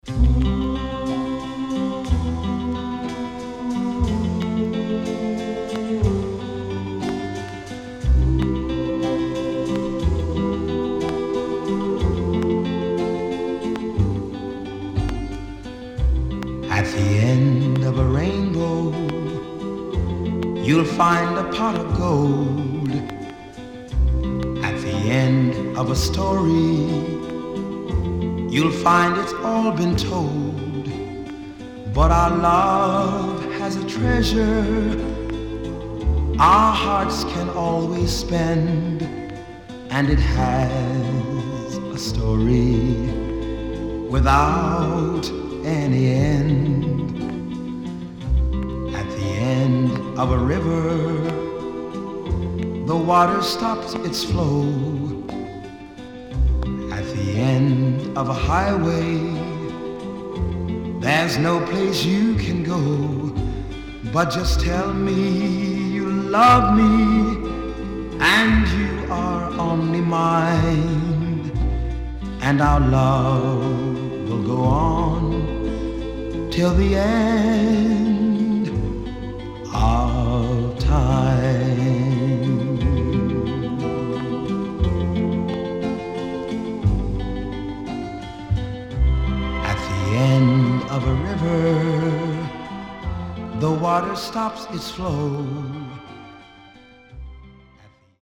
SIDE A:序盤プチノイズ入ります。